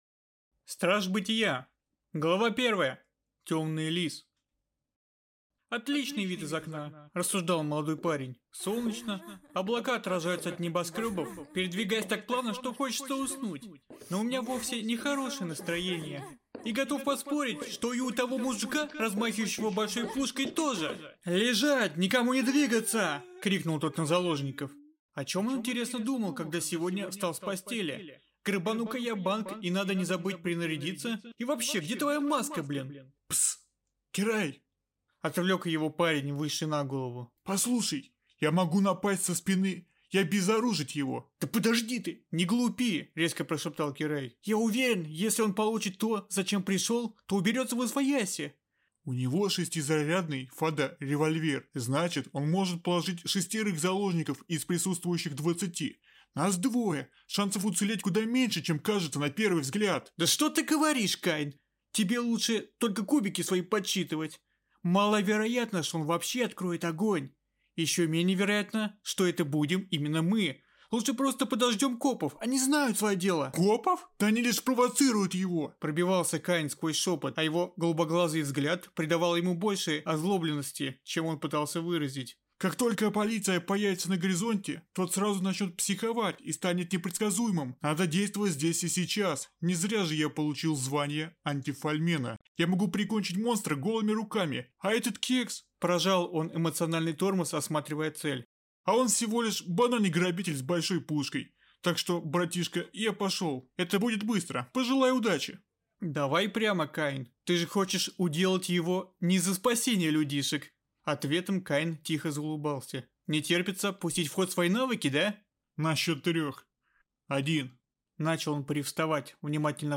Аудиокнига СТРАЖ БЫТИЯ | Библиотека аудиокниг